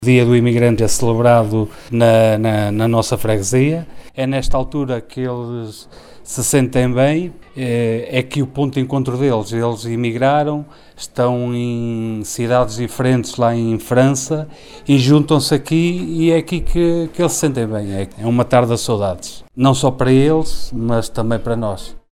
Cristovão Chaves, Presidente da Junta de Freguesia de Queiriga, disse que este “Dia do Emigrante” representa o ponto de encontro dos emigrantes com as suas famílias “é aqui que se sentem bem…”, “é o matar das saudades…”.